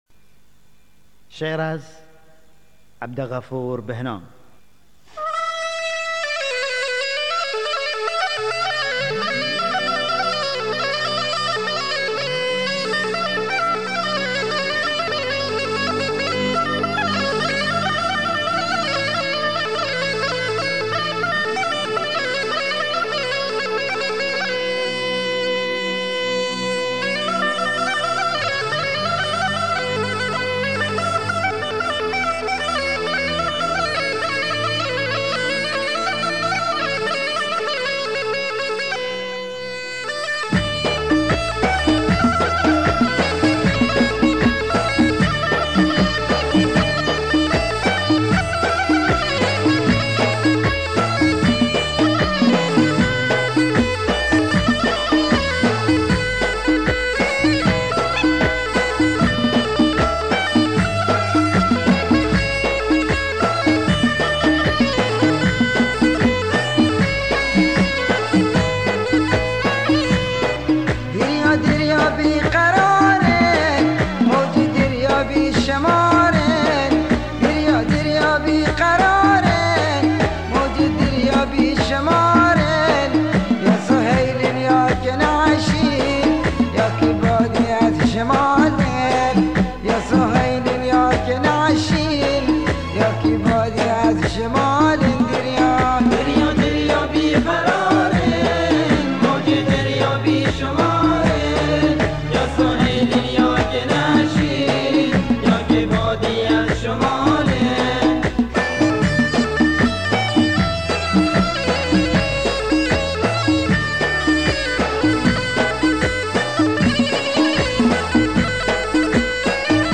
بندری
آهنگ‌های بندری